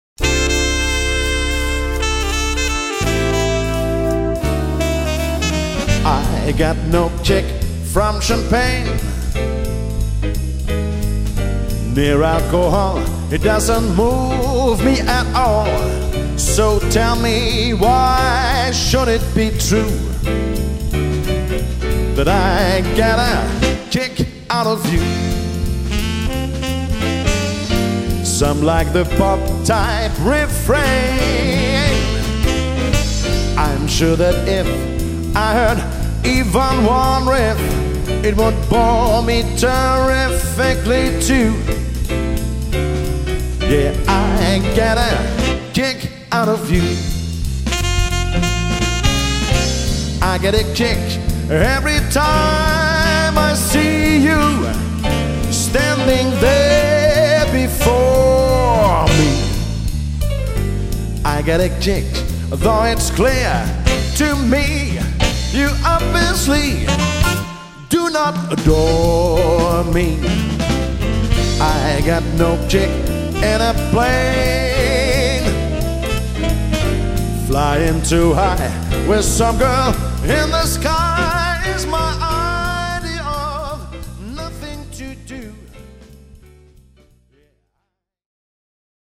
LAS VEGAS SHOW